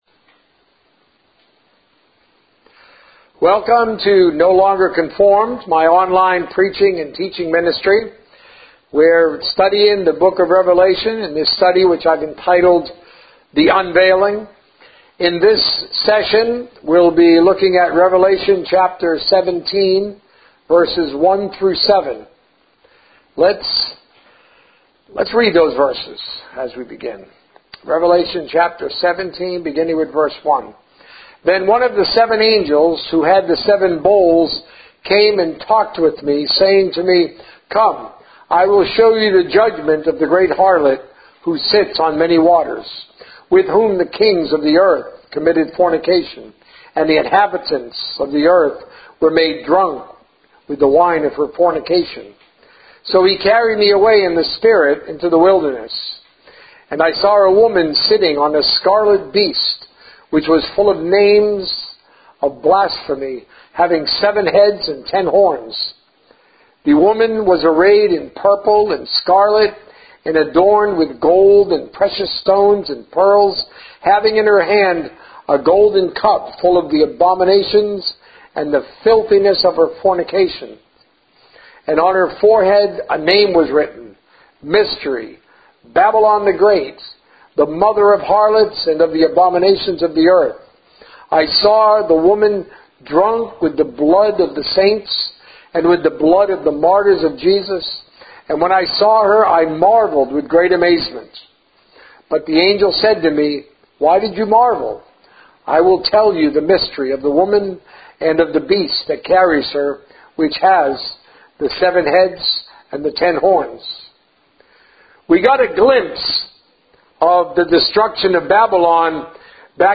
A message from the series "Learning to Thrive."